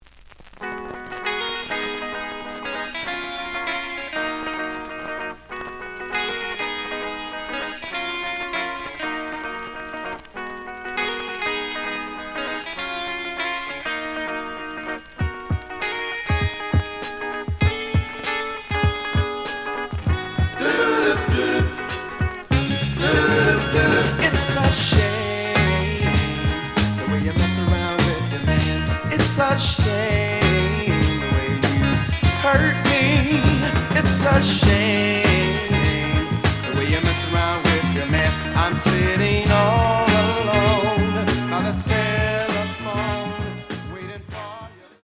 The audio sample is recorded from the actual item.
●Genre: Soul, 70's Soul
Some noise on beginning of A side, but almost good.)